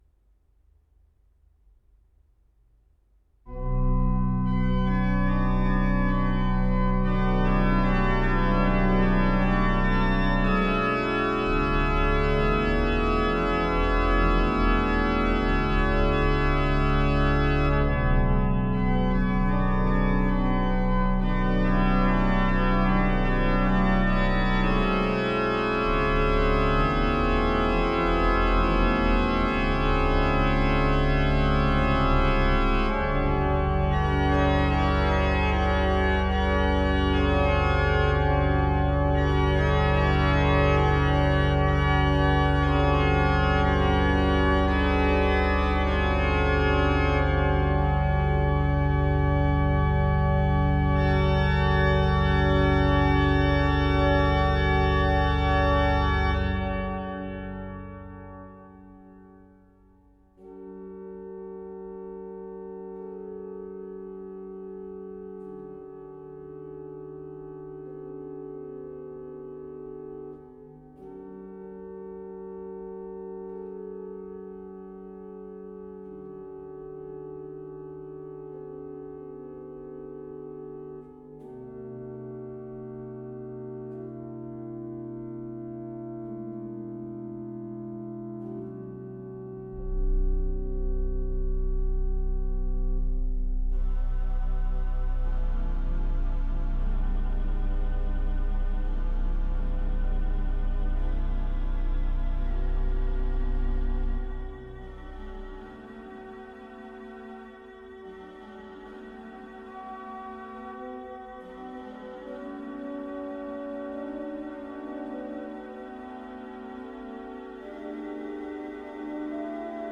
for Organ Click to listen.